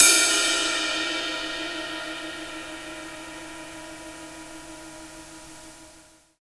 BELL_hard_04.WAV